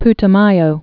(ptə-mīō, -t-mäyō)